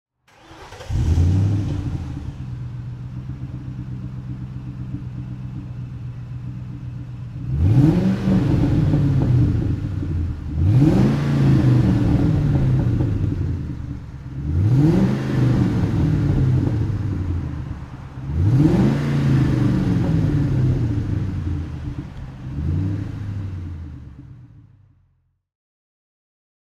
Qvale Mangusta (2002) - Starten und Leerlauf
Qvale_Mangusta_2002.mp3